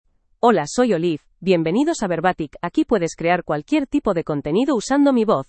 Olive — Female Spanish (Spain) AI Voice | TTS, Voice Cloning & Video | Verbatik AI
Olive is a female AI voice for Spanish (Spain).
Voice sample
Listen to Olive's female Spanish voice.
Olive delivers clear pronunciation with authentic Spain Spanish intonation, making your content sound professionally produced.